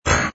ui_select_remove.wav